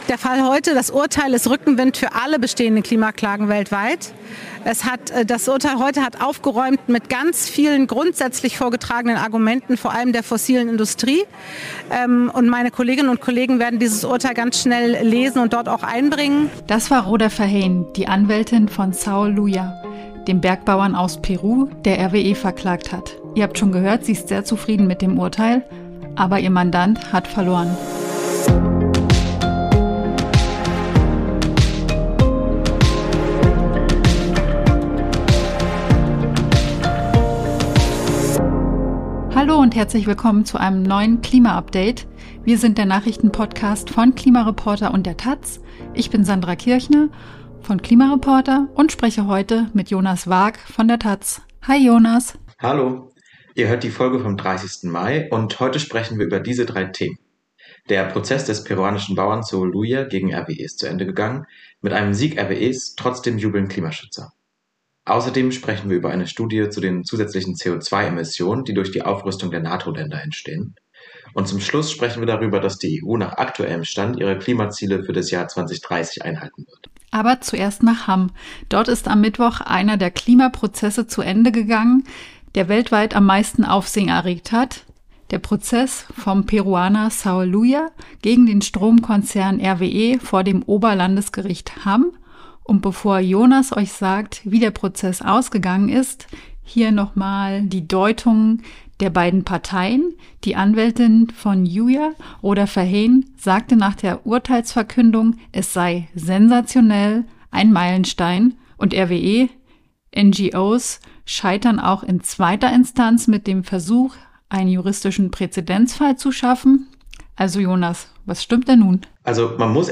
Im klima update° besprechen Journalistinnen vom Online-Magazin klimareporter° und von der Tageszeitung taz jeden Freitag die wichtigsten Klima-Nachrichten der Woche.